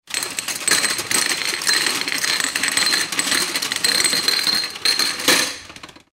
Llevar unos vasos de tubo en una bandeja